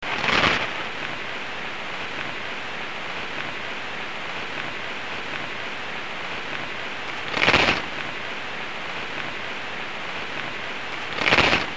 chorthippus_parallelus.mp3